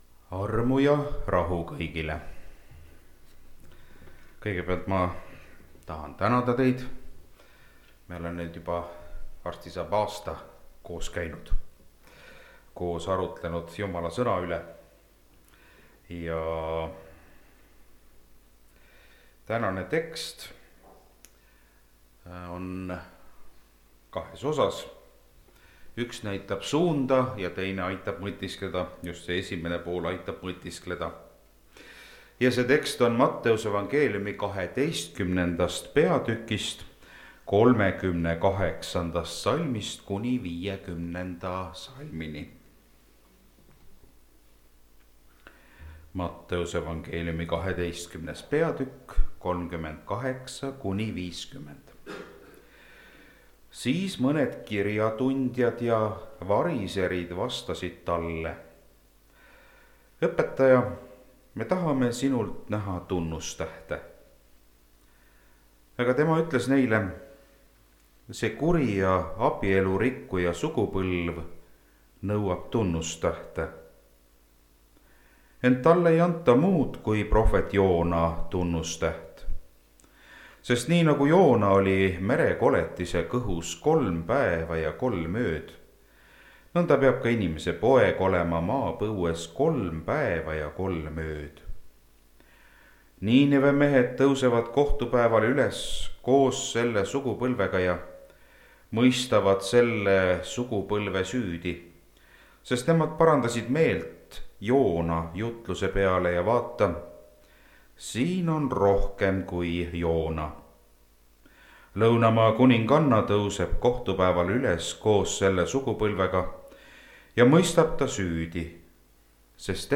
(Rakveres)
Jutlused